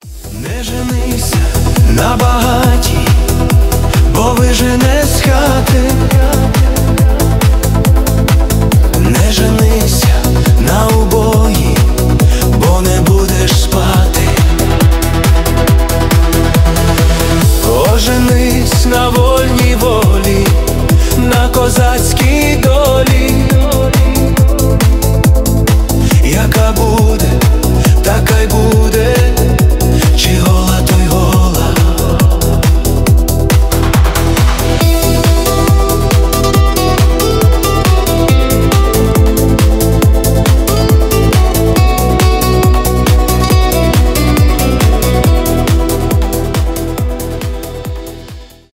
поп , нейросеть